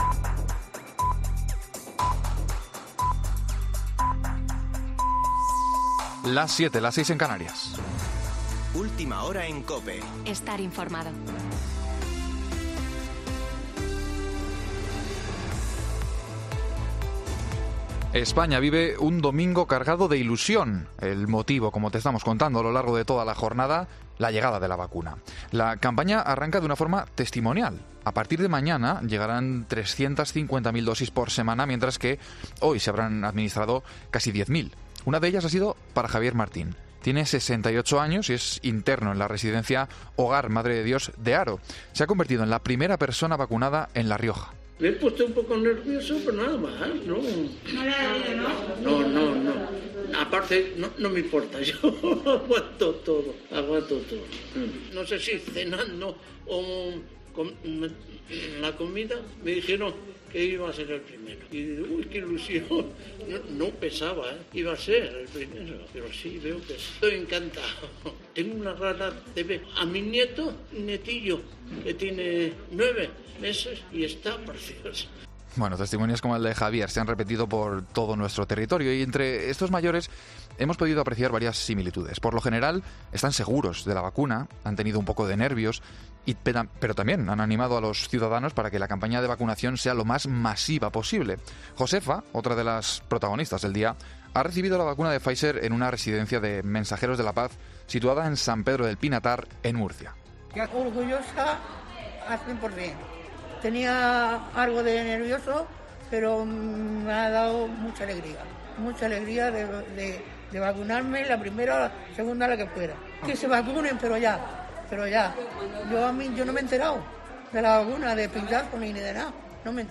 Boletín de noticias de COPE del 27 de diciembre de 2020 a las 20.00 horas